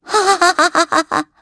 Gremory-Vox_Happy3_kr.wav